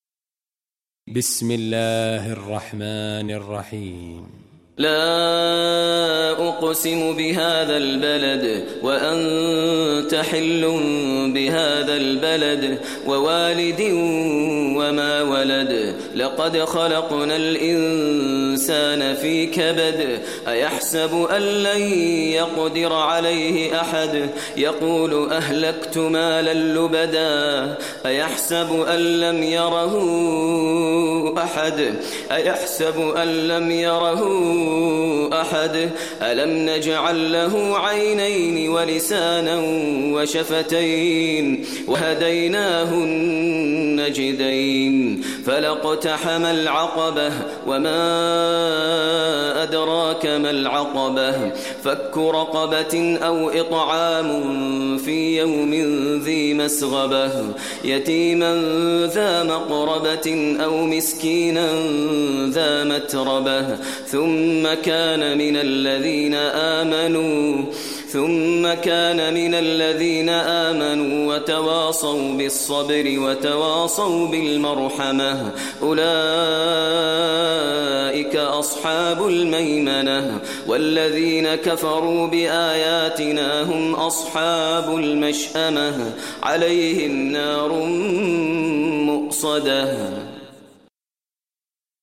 Surah Balad Recitation by Maher al Mueaqly
Surah Balad, listen online mp3 tilawat / recitation in Arabic recited by Imam e Kaaba Sheikh Maher al Mueaqly.